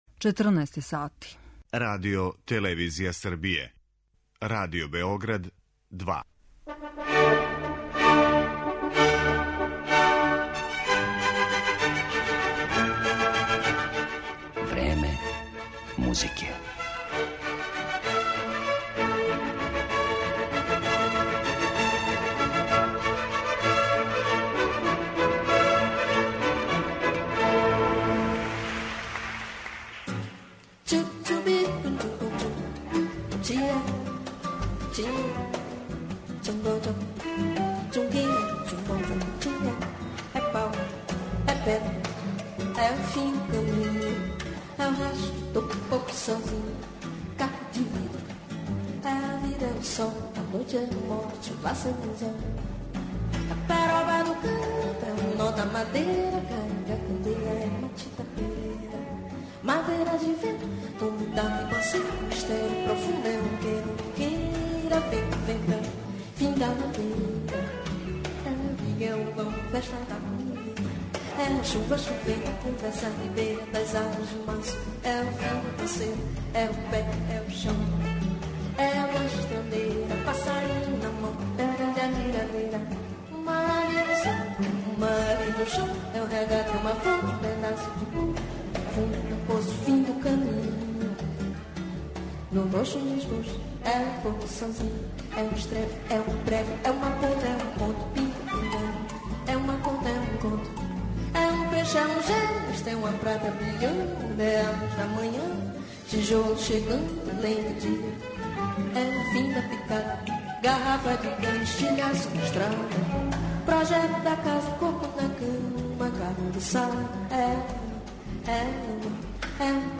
Класична музика Бразила